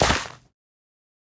Minecraft Version Minecraft Version latest Latest Release | Latest Snapshot latest / assets / minecraft / sounds / block / suspicious_gravel / step2.ogg Compare With Compare With Latest Release | Latest Snapshot